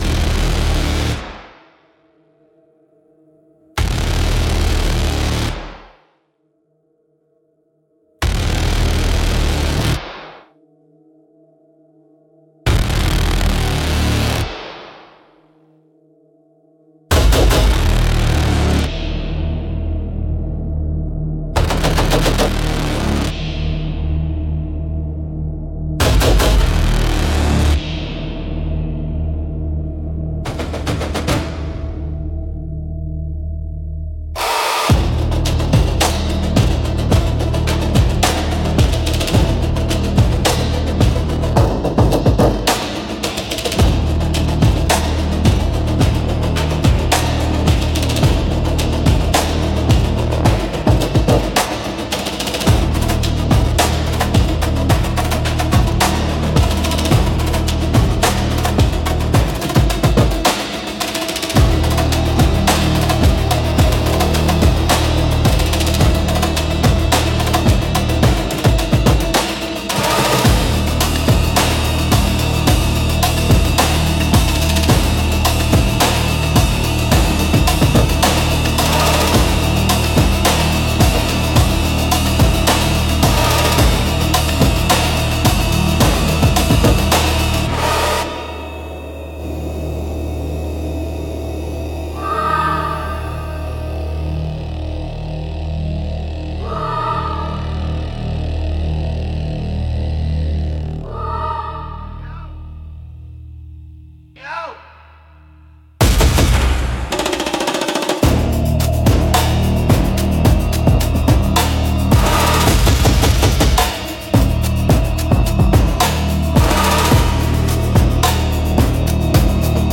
Instrumental - Ritual of Steel and Smoke -2.32